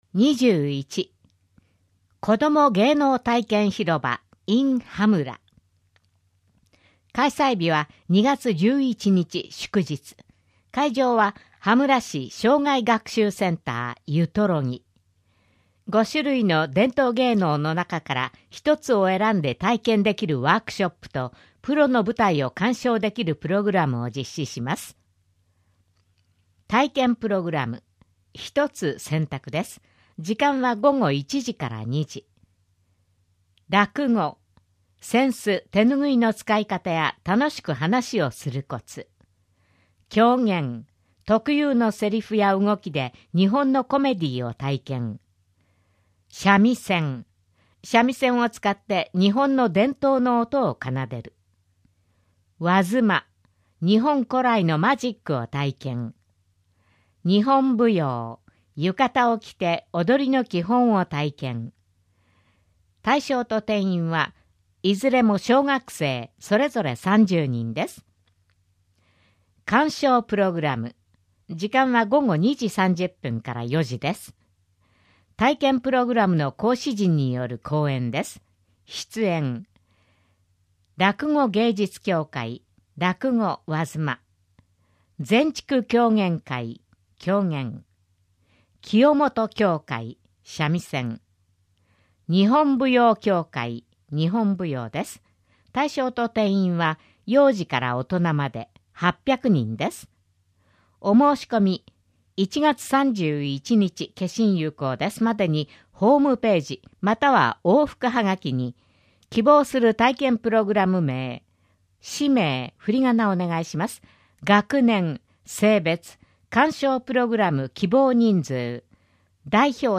広報東京都 音声版」は、視覚に障害のある方を対象に「広報東京都」の記事を再編集し、音声にしたものです。